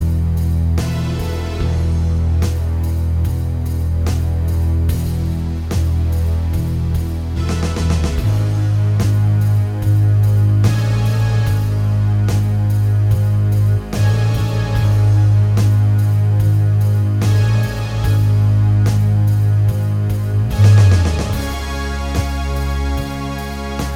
Minus All Guitars Rock 6:28 Buy £1.50